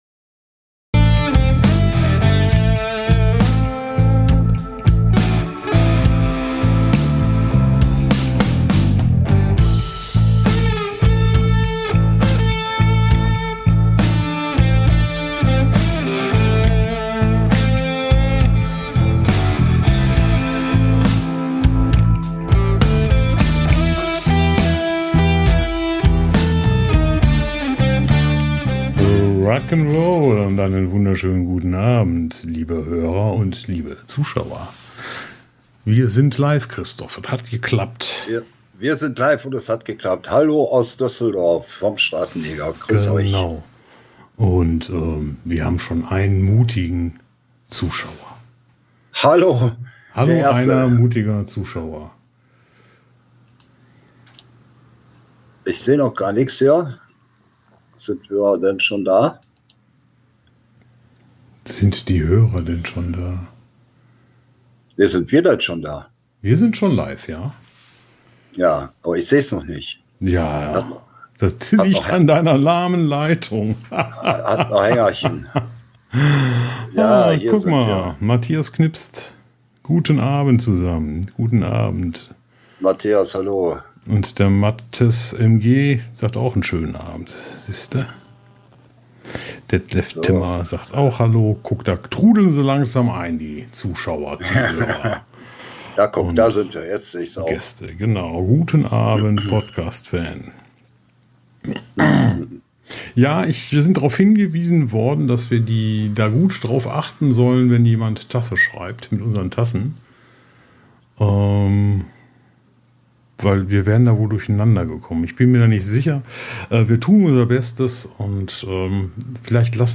Aufzeichnung von Live am Sonntag